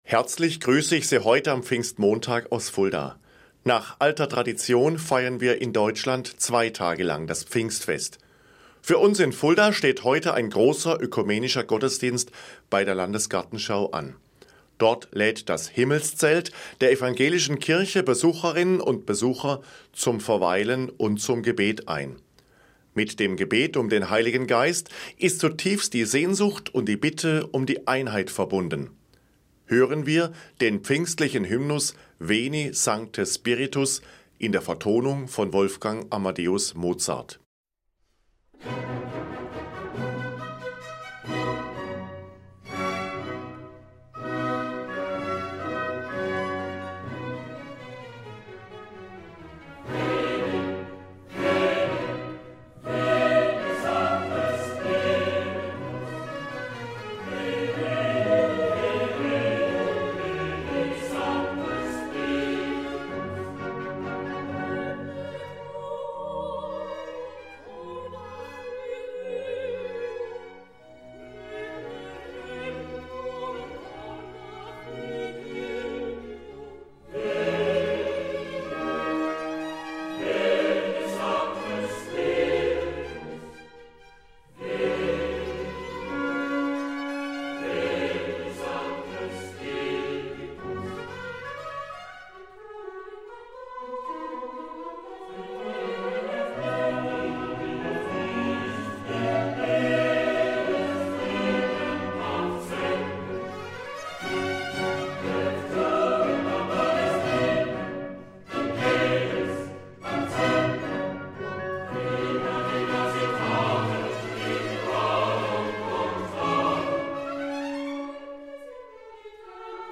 Eine Sendung von Dr. Michael Gerber, Bischof von Fulda
Hören wir den pfingstlichen Hymnus „Veni sancte spiritus“ in der Vertonung von Wolfgang Amadeus Mozart.